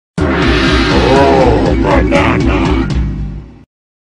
Voice: "OH, BANANA"
Donkey-kong-64-oh-banana.oga.mp3